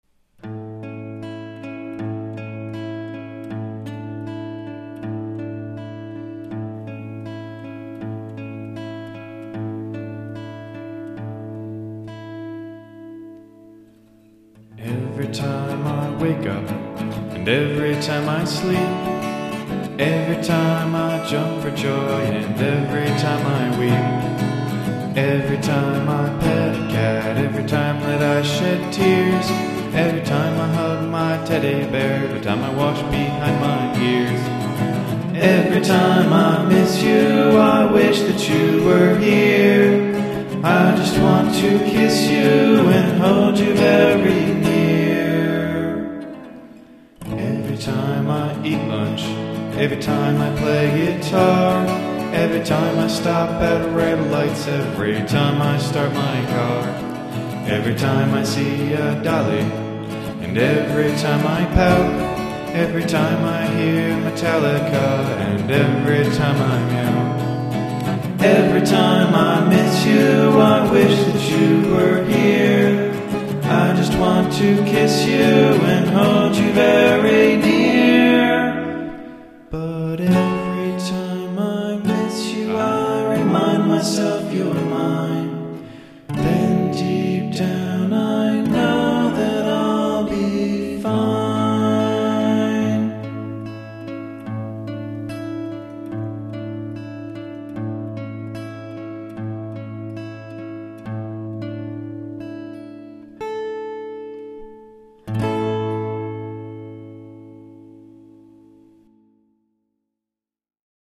Recently re-recorded for better stereo and fidelity.